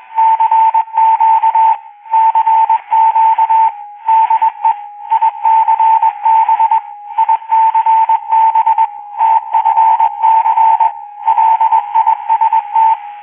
Chiamata di Genova Radio ICB